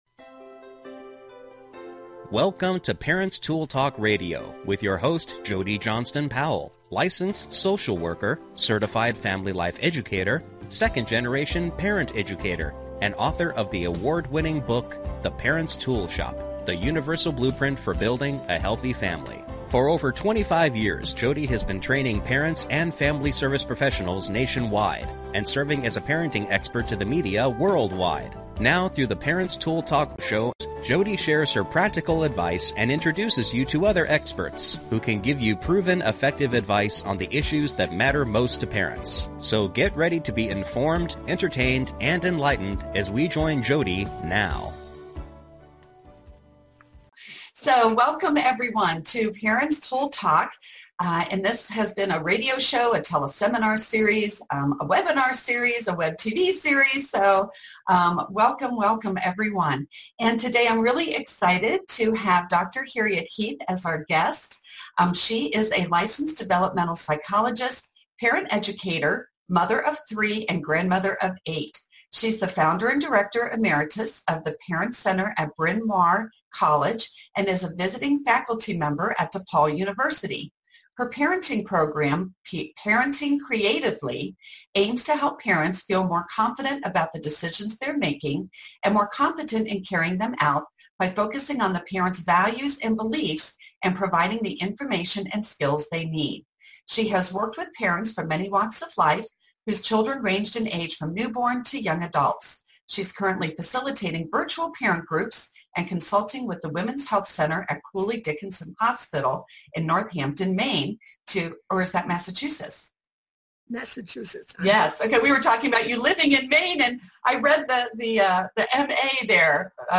Featuring an interview